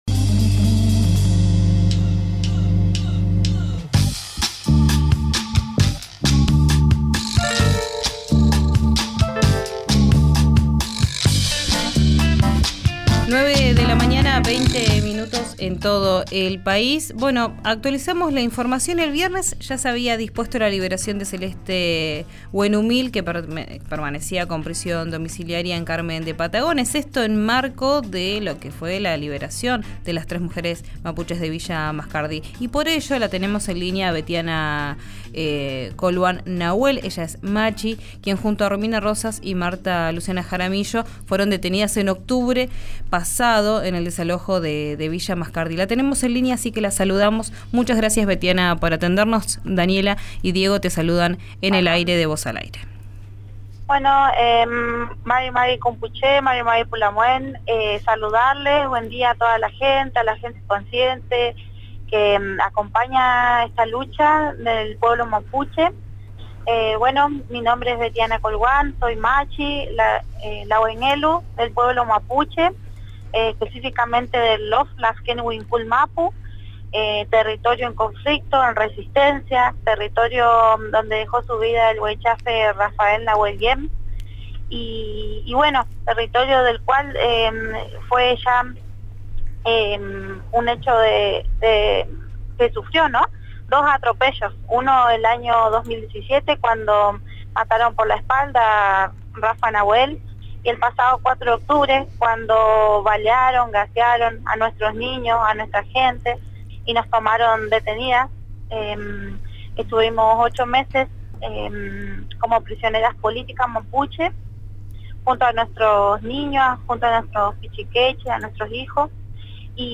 en diálogo con RÍO NEGRO RADIO.